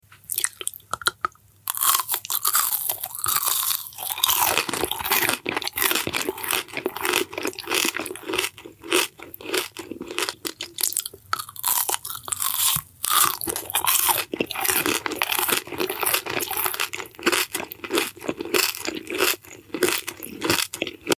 ASMR Satisfying Eating Crunchy Pepper